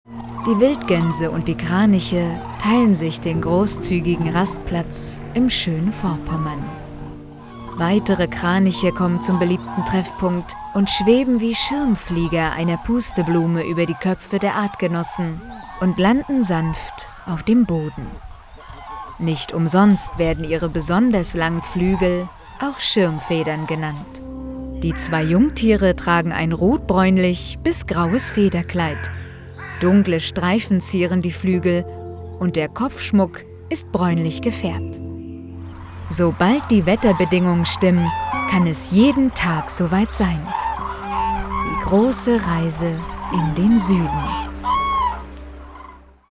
Doku Kraniche warm, sanft, entschleunigend
Doku